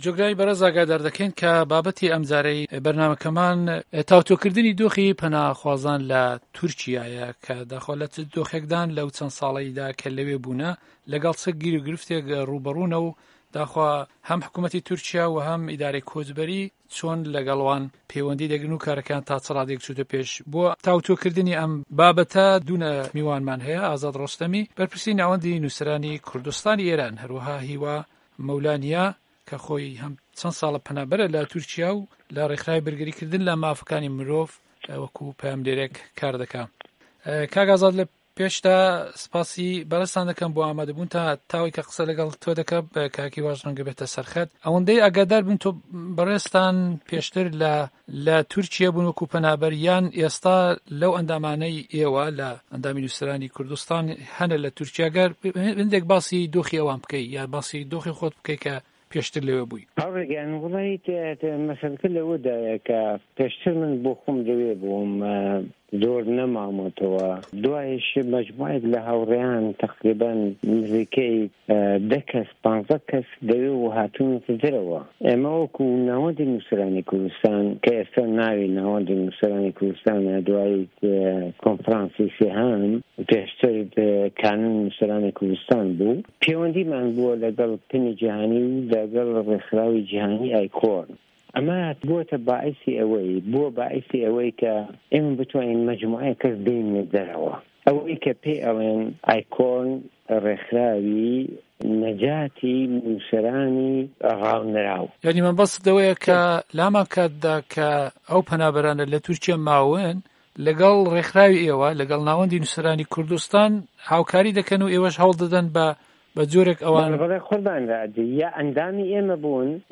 مێزگرد